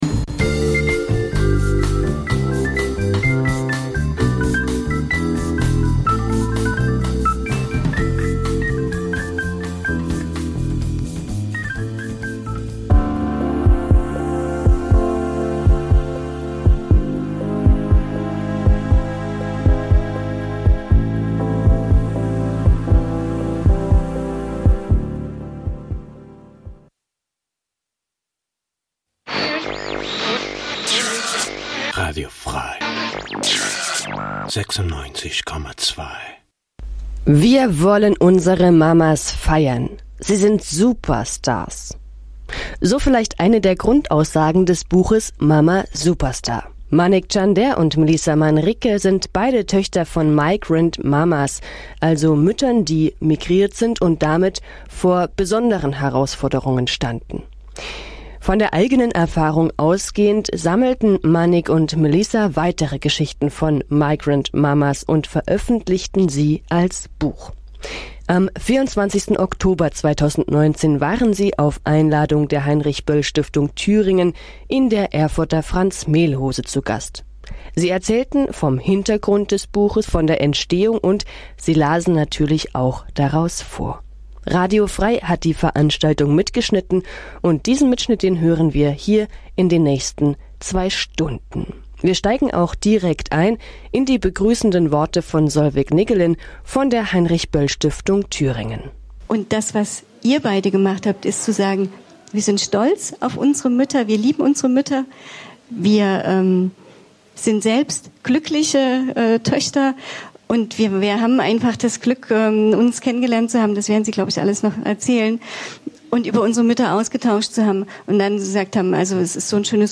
Aufzeichnung vom 24. Oktober 2019, Franz Mehlhose
Lesung "Mama Superstar" Dein Browser kann kein HTML5-Audio.